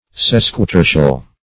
Sesquitertial \Ses`qui*ter"tial\